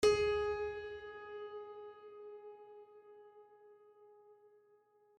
piano-sounds-dev
gs3.mp3